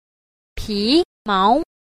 1. 皮毛 – pímáo – bì mao (da lông, bề mặt, nông cạn)